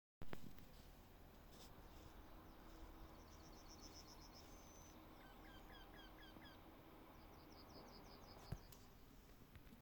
Putni -> Dzeņi ->
Tītiņš, Jynx torquilla
StatussDzied ligzdošanai piemērotā biotopā (D)